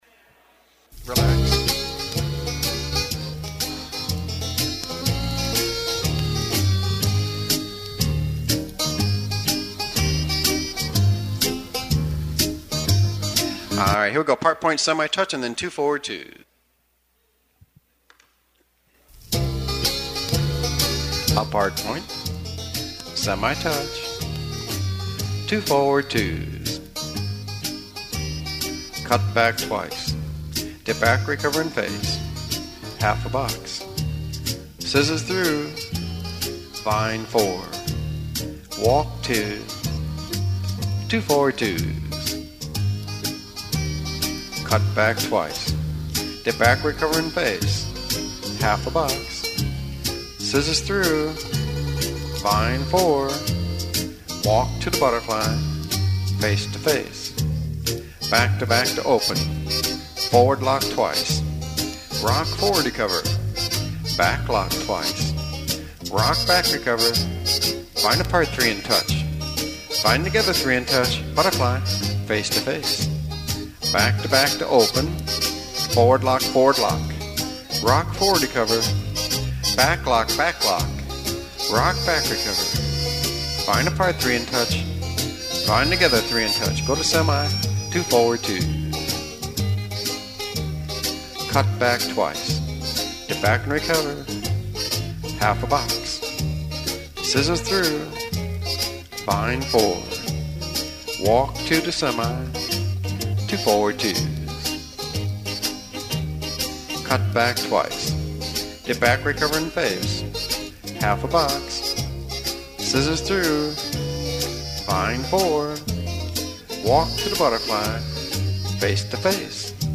Cued Music
Two Step